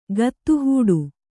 ♪ gattu hūḍu